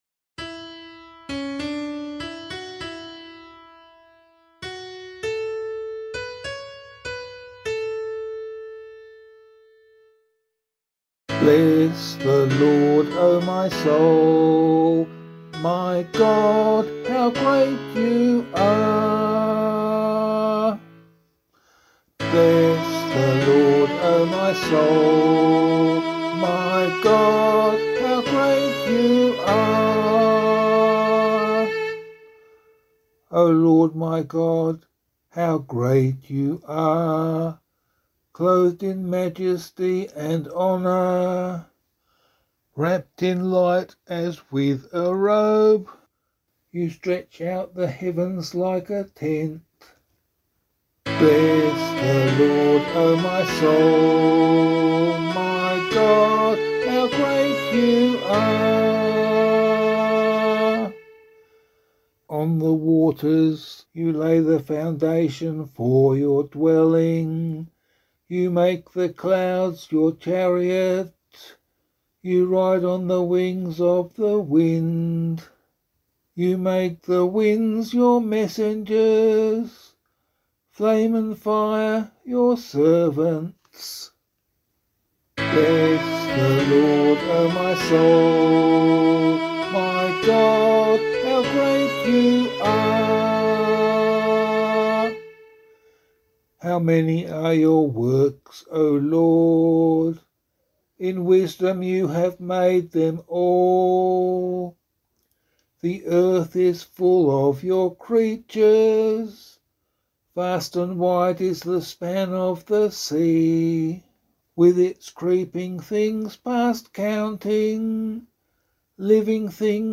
The Holy Family of Jesus, Mary and Joseph: Responsorial Psalm, Year C option
011 Baptism of the Lord Psalm C [APC - LiturgyShare + Meinrad 8] - vocal.mp3